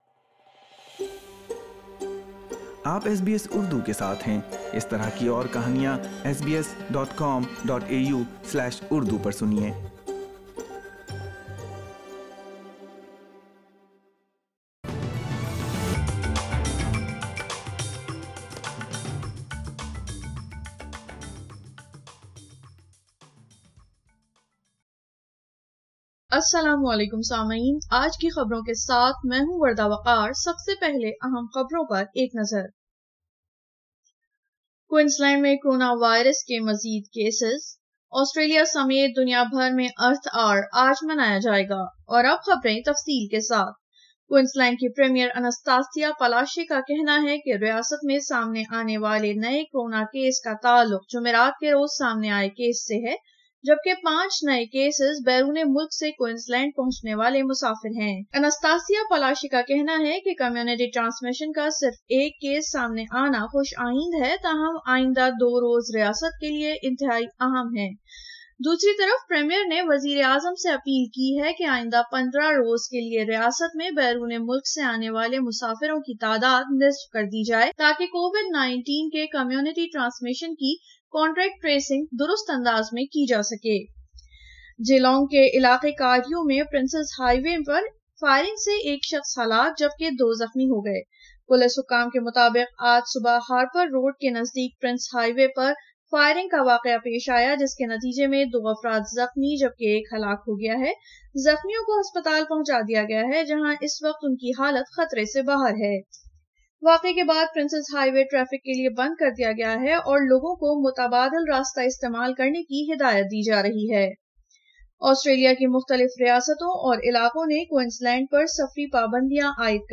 اردو خبریں 27 مارچ 2021